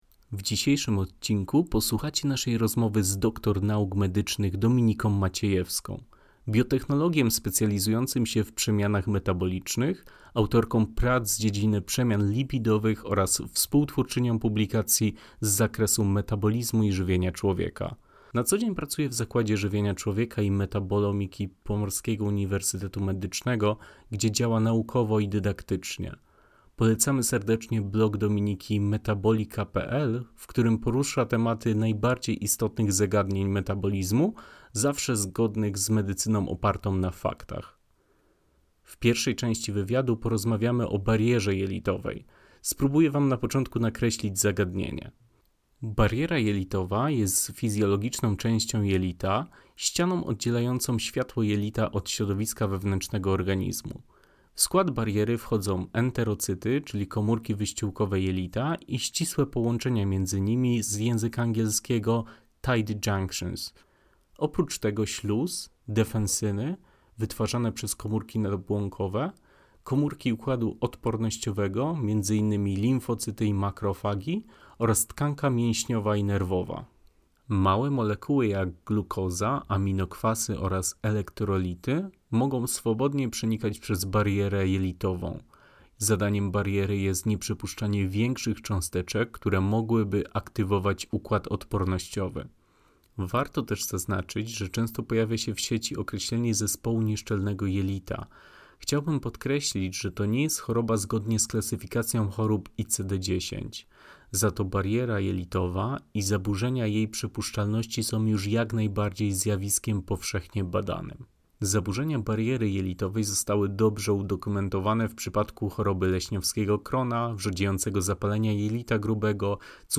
Czym jest bariera jelitowa – Wywiad